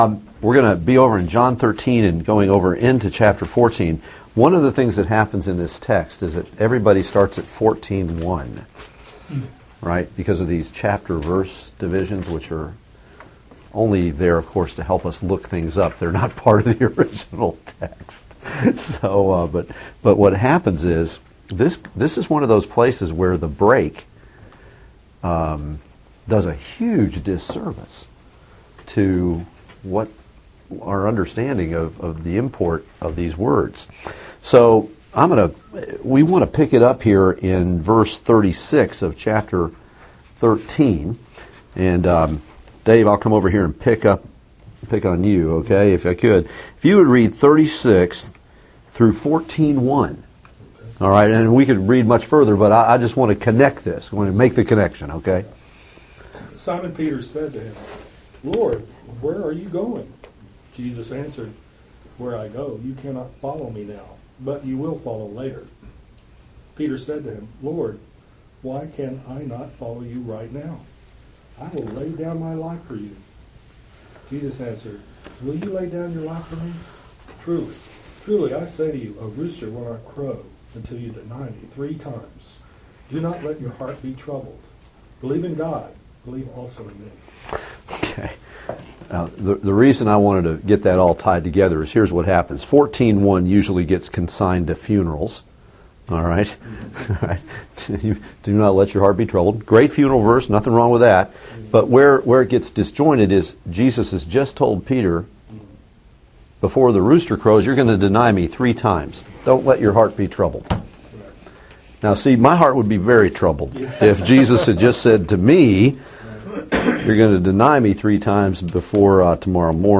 Passage: John 13:36-14:14 Service Type: Bible Study